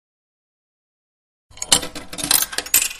Coins Jingling
Coins Jingling is a free foley sound effect available for download in MP3 format.
021_coins_jingling.mp3